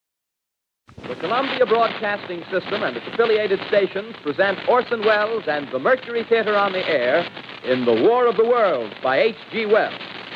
Orson-Welles-War-Of-The-Worlds-OldGrainy.mp3